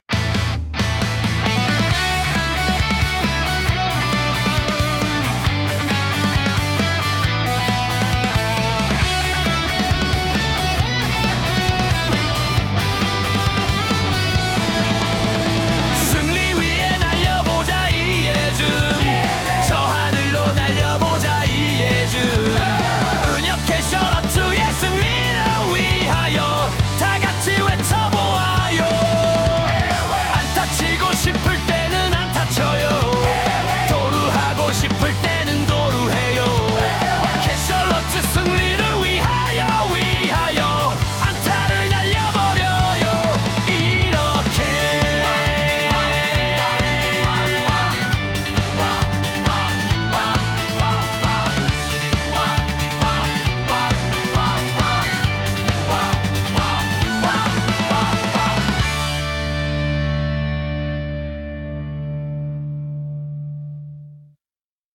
응원가